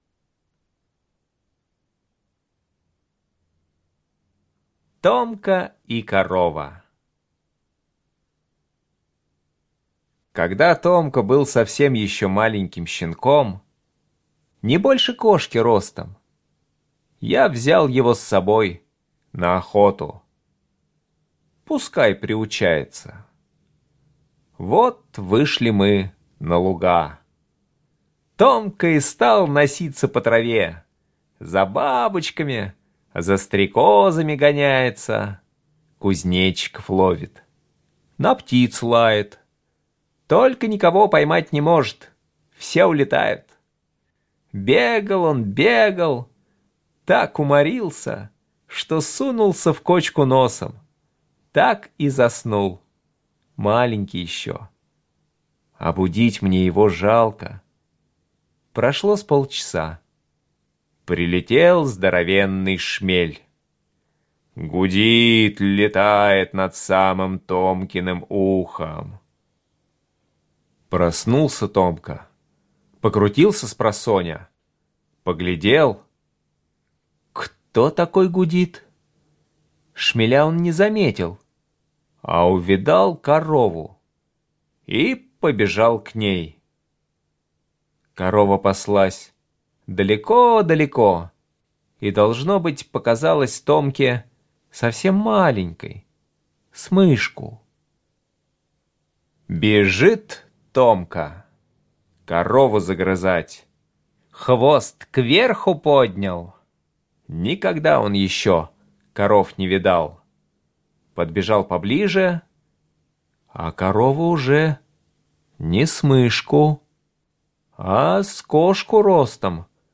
Слушайте Томка и корова - аудио рассказ Чарушина Е.И. Рассказ, как автор с собакой Томкой ходили на охоту. Вдалеке на лугу Томка увидел корову.